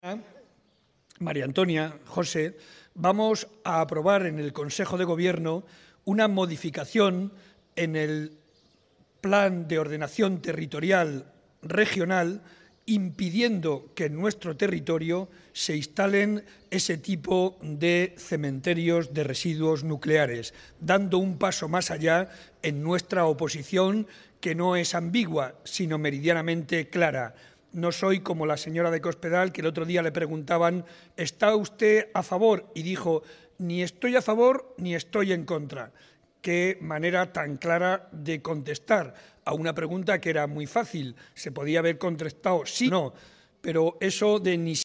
El presidente de Castilla-La Mancha y candidato a la reelección, José María Barreda, anunció hoy en Yunquera de Henares (Guadalajara), a los pies de la «la dama de la Campiña», que el Gobierno va a dar un paso más para alejar la posibilidad de que se instale un cementerio de residuos nucleares en Guadalajara o en cualquier otro punto de nuestra Región.